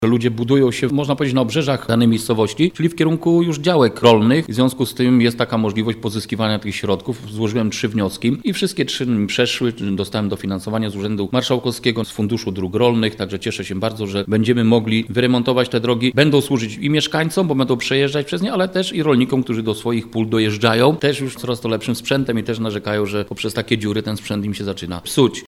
– Często jesteśmy proszeni przez mieszkańców, aby umożliwić po prostu dojazd do domu – tłumaczy Leszek Mrożek, wójt gminy Żary.